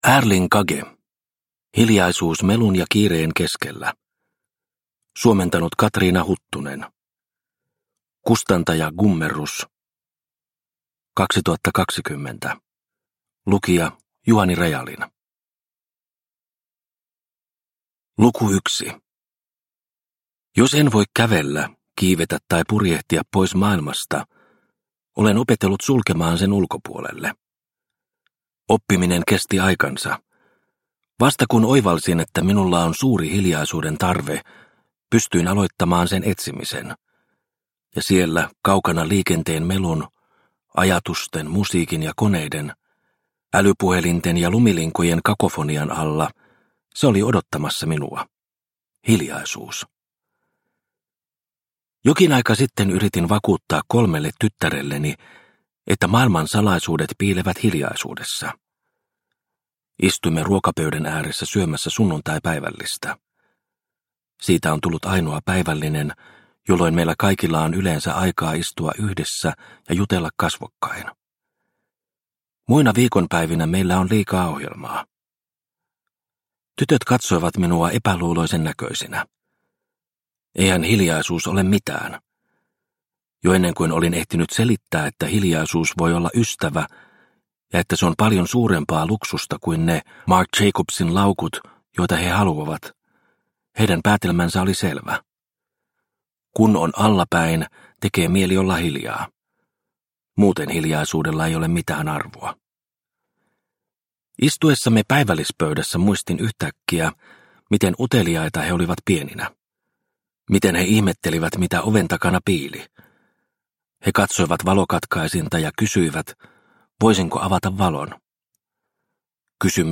Hiljaisuus – Ljudbok – Laddas ner